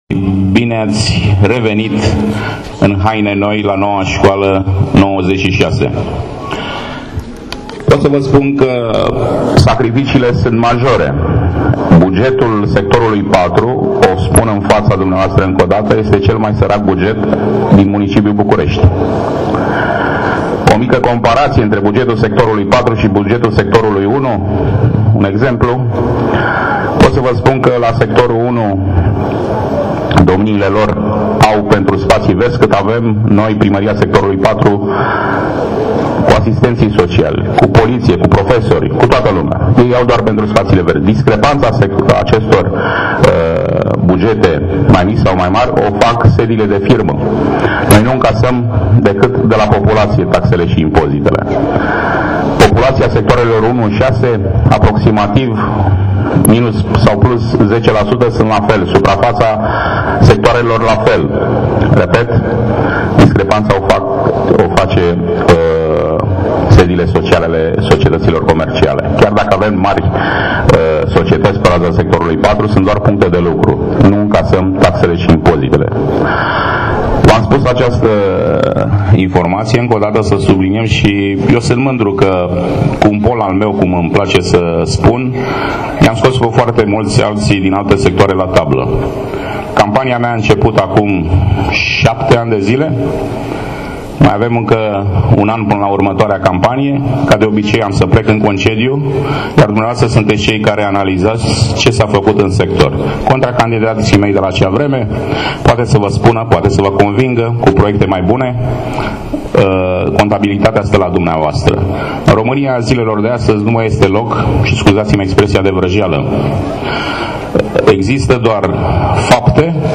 Edilul Cristian Popescu – Piedone a deschis oficial noul an de invatamant la Scoala numarul 96, de pe strada Rezonantei
Aproximativ 650 de elevi, insotiti de parinti si bunici au participat la deschiderea noului an de invatamant, la Scoala 96.